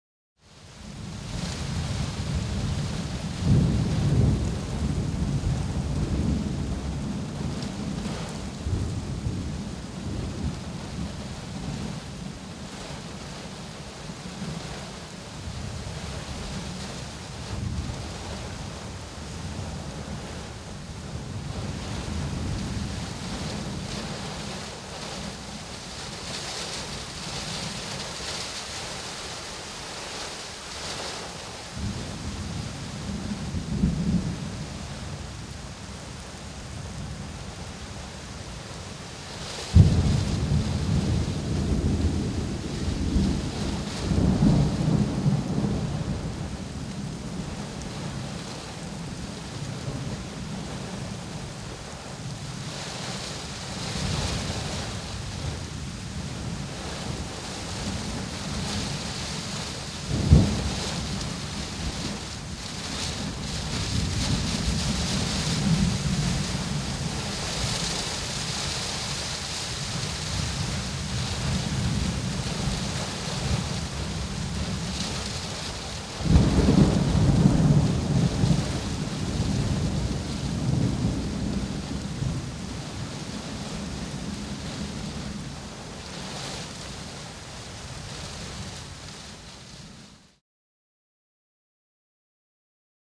Звуки погоды
Дождь стучит по окну, грохочет гром